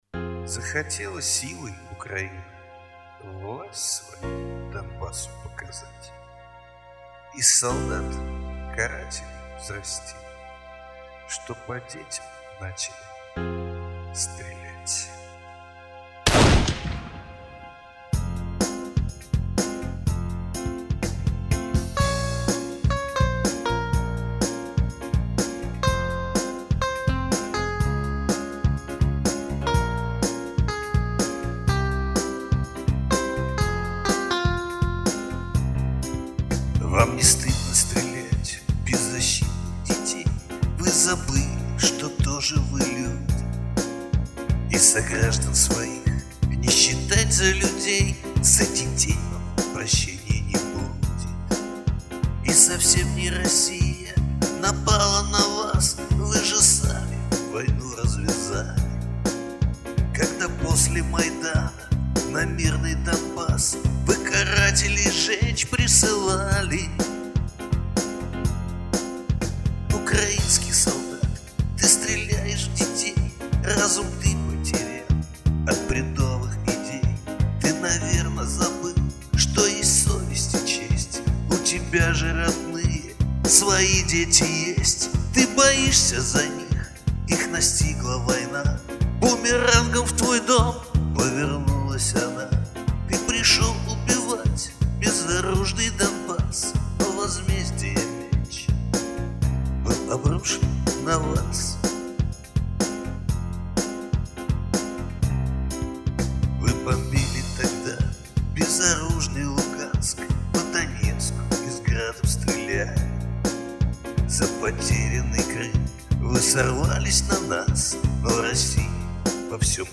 Русский поп-шансон